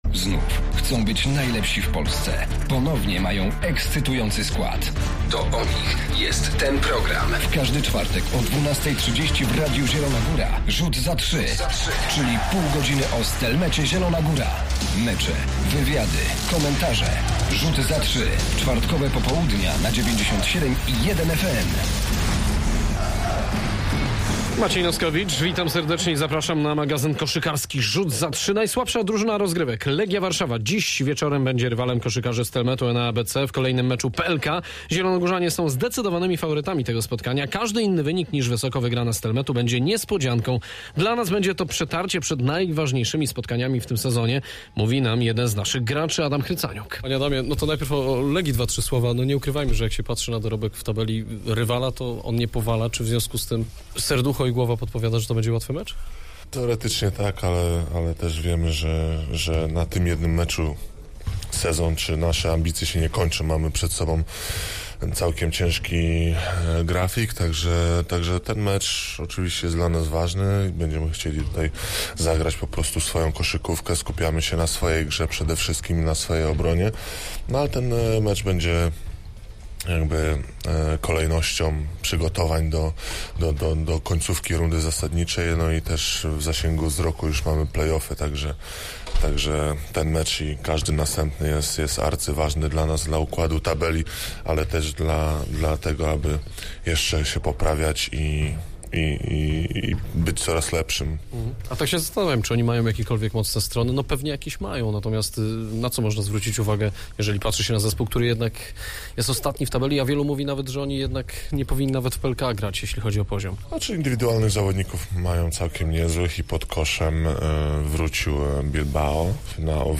Zapraszamy na półgodzinny magazyn koszykarski „Rzut za trzy”